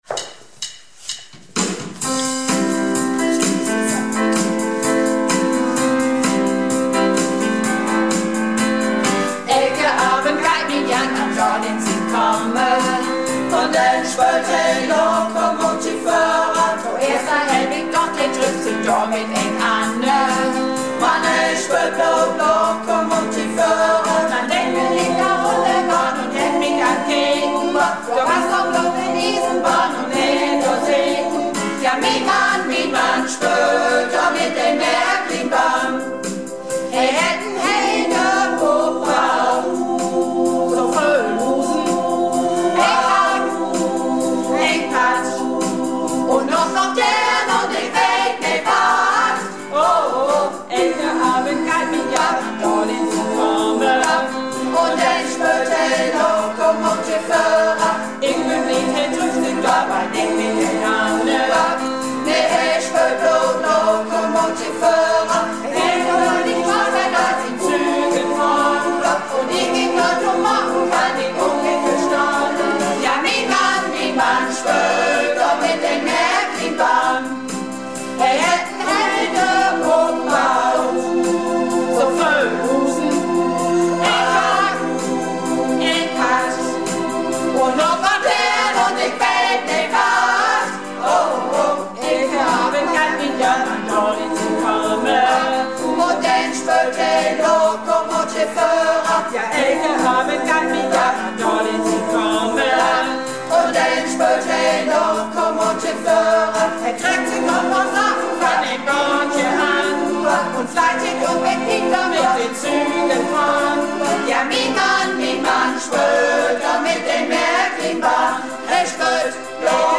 Malle Diven - Probe am 11.02.16